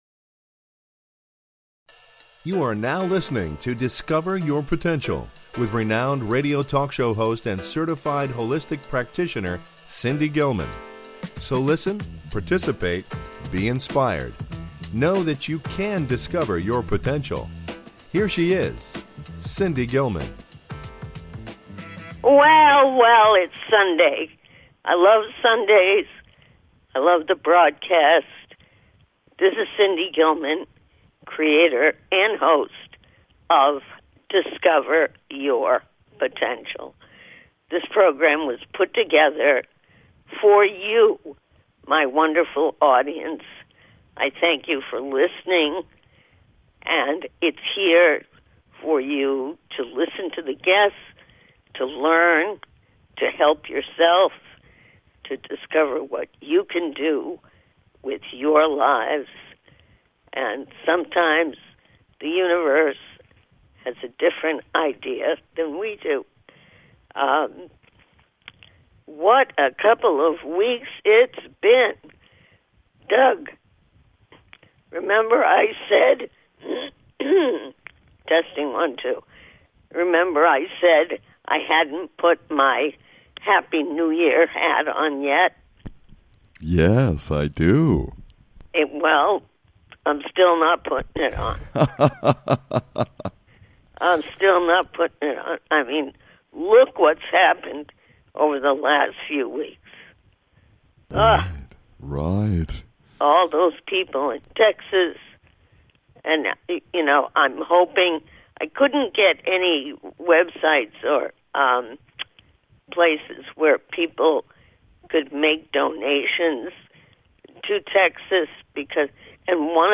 Guest, Jon Land, renowned author of international acclaim